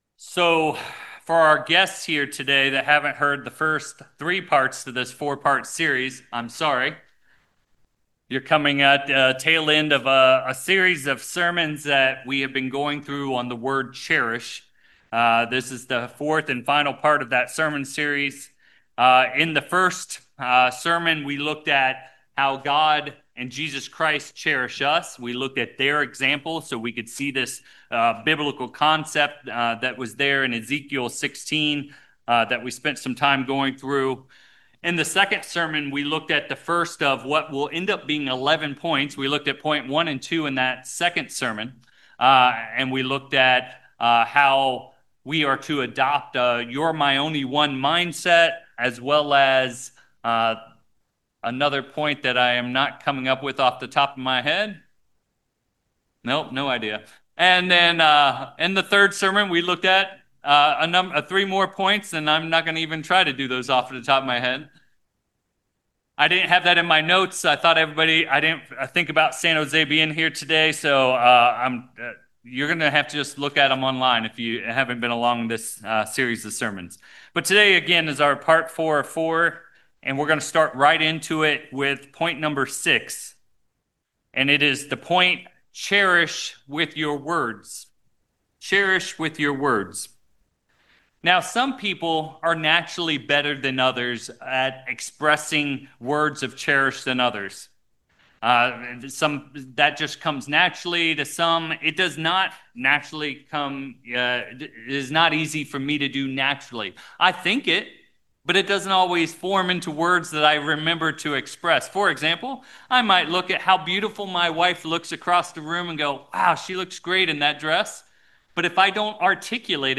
9/6/25 This final sermon in the Cherish series shows how cherishing our spouse is lived out daily—through uplifting words, tender touch, and truly knowing our “one and only.” It’s about countless small choices that reflect the way God and Christ cherish us.